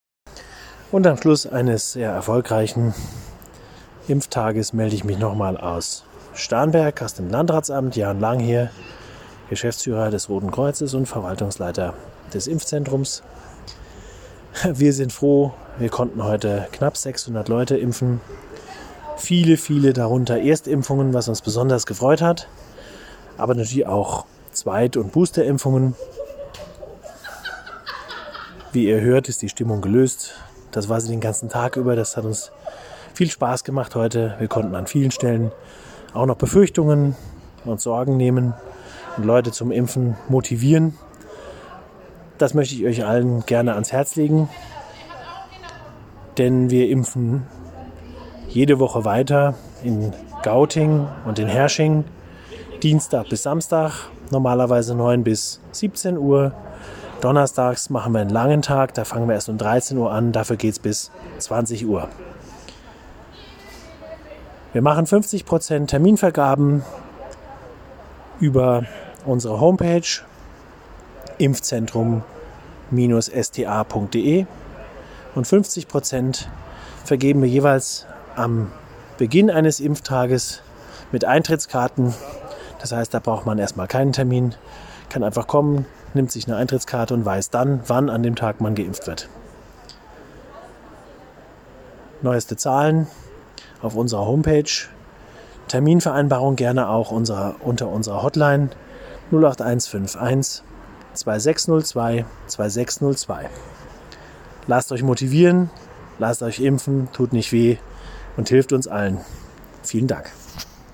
Hier ein Schlussinterview